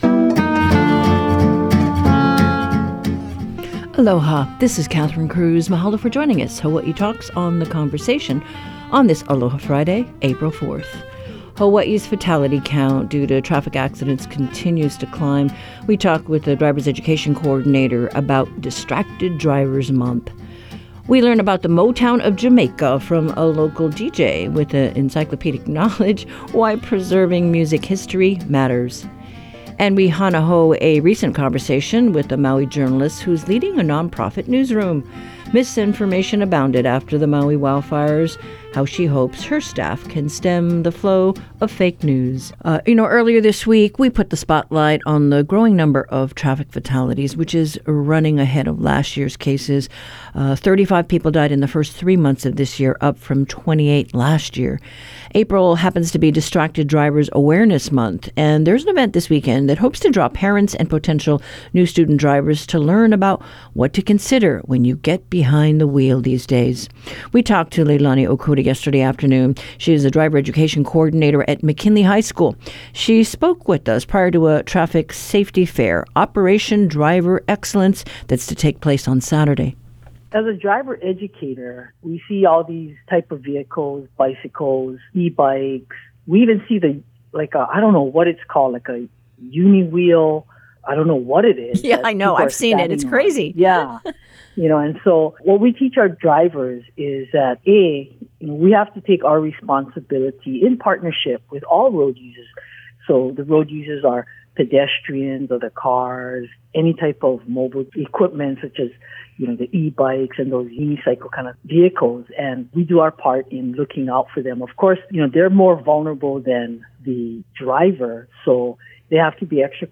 Whether you live in our state or far from our shores, you’ll know what’s happening in Hawaiʻi with HPR's daily hour of locally focused discussions of public affairs, ideas, culture and the arts. Guests from across the islands and around the world provide perspectives on life in Hawaiʻi — and issues that have not yet reached Hawaiʻi.